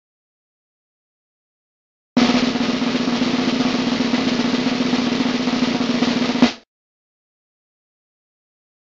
Drum Roll 3 Sound Effect Free Download
Drum Roll 3